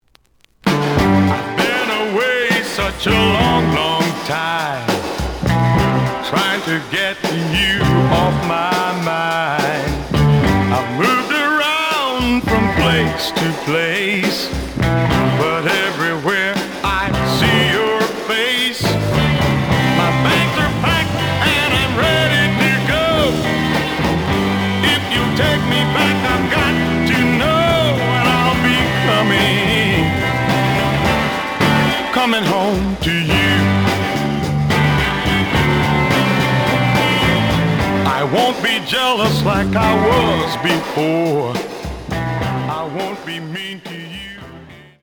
The audio sample is recorded from the actual item.
●Genre: Soul, 60's Soul
B side plays good.